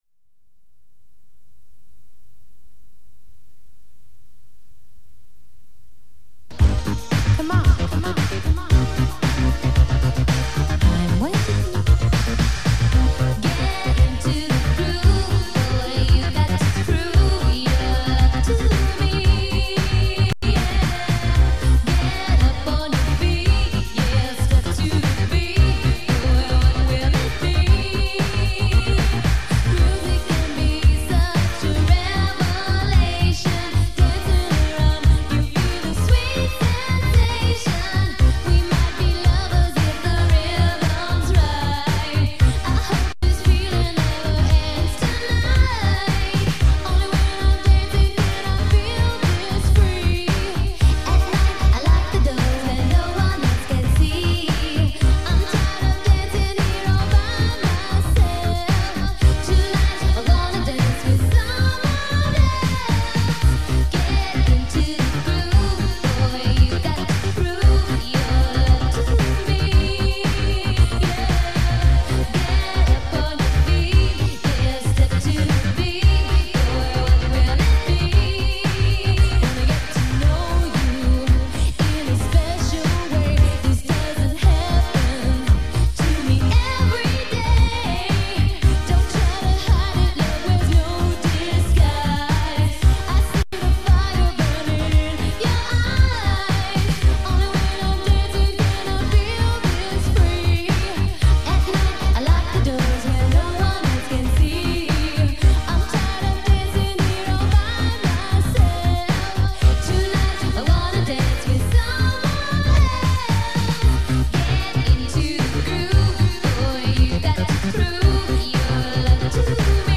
Неизвестная запись с кассеты